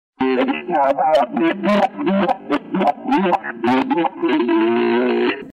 Una registrazione fresca, dove si sente la contentezza del Santo di poter di nuovo comunicare con noi e che fa da perfetto seguito all'esperienza dei volti ricevuti tramite le onde radio per intercessione e volontà dello stesso Sant'Erasmo.